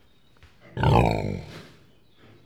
animals